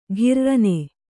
♪ ghirranne